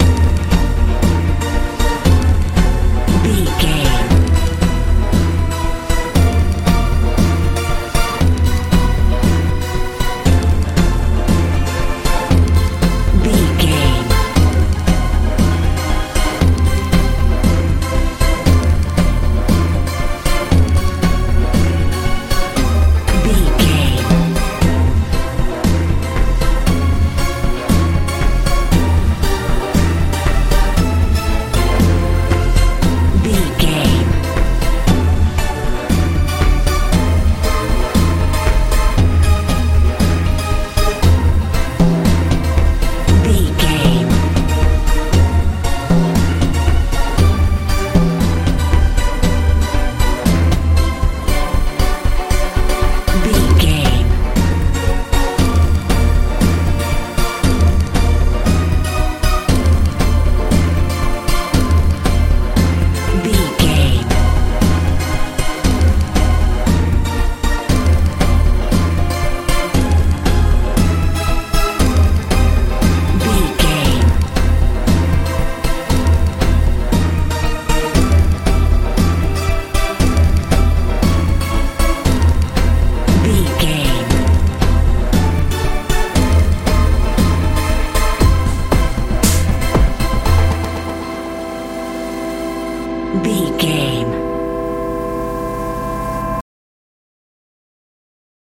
k-pop feel
Ionian/Major
B♭
intense
powerful
synthesiser
bass guitar
drums
80s
90s
strange
suspense